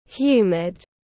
Độ ẩm cao tiếng anh gọi là humid, phiên âm tiếng anh đọc là /ˈhjuː.mɪd/.
Humid /ˈhjuː.mɪd/